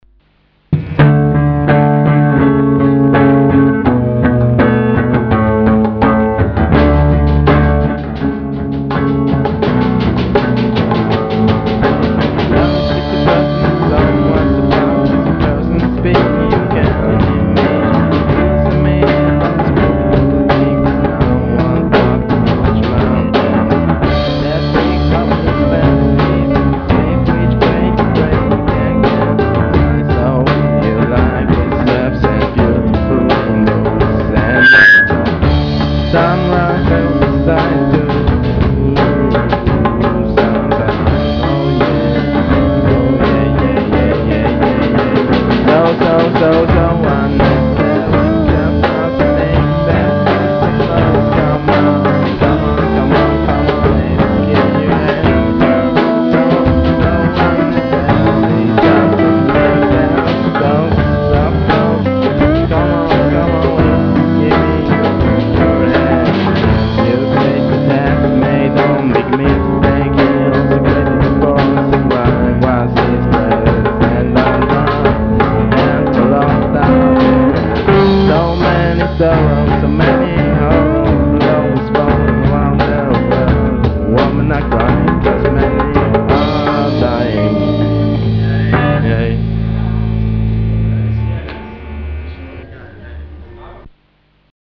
gesang, drums
djembe
gitarren, bass